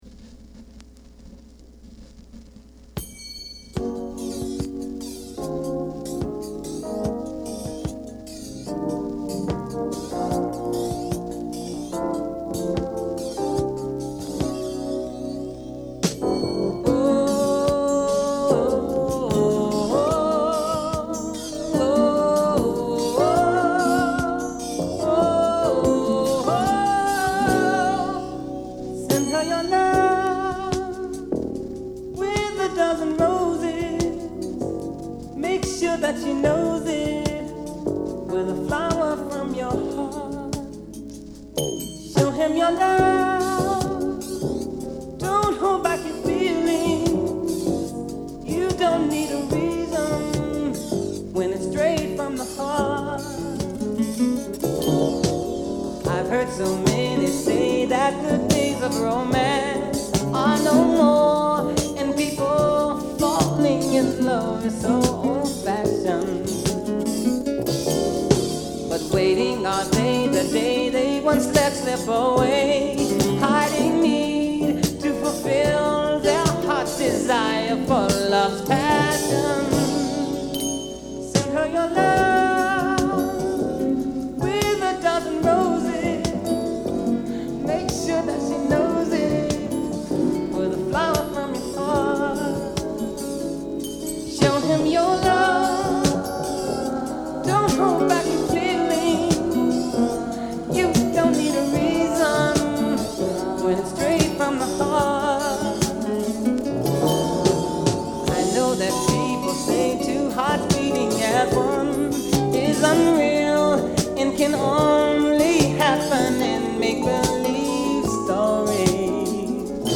Genre: Soul / Ambient / Soundtrack
このアルバムで最もポップに振り切った一曲。